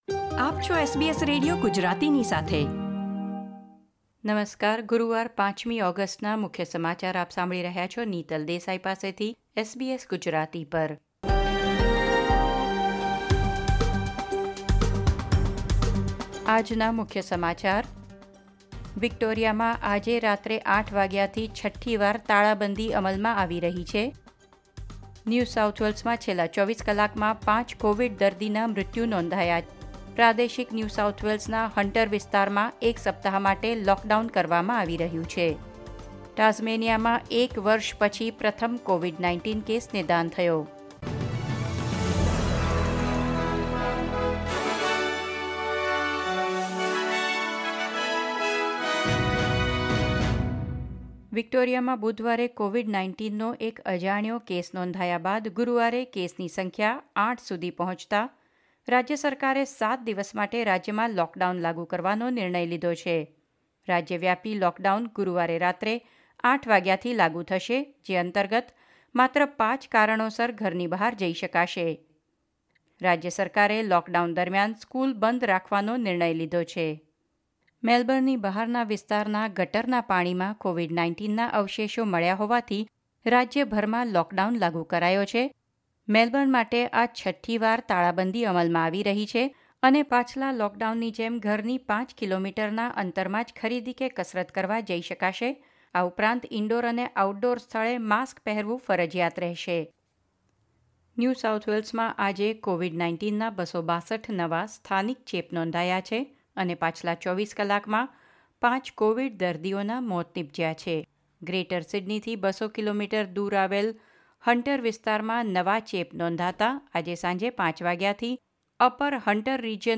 SBS Gujarati News Bulletin 5 August 2021